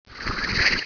1 channel
SHUFFLE.WAV